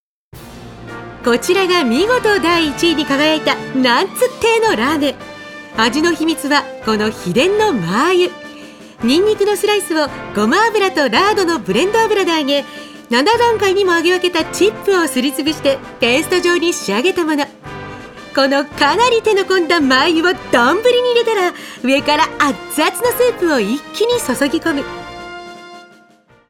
アナウンサー
太くて強い声が特徴で、強靭な声帯の持ち主。
ボイスサンプル